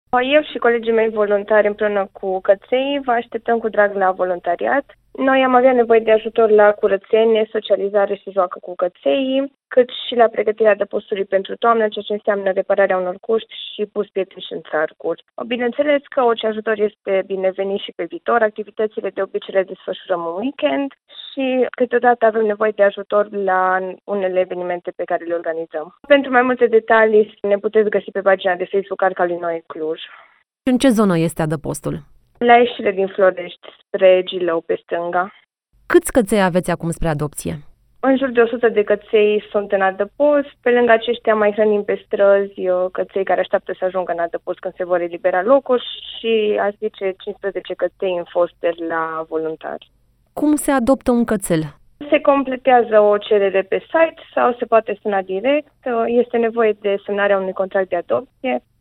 Aflați cum puteți ajuta, în interviul acordat de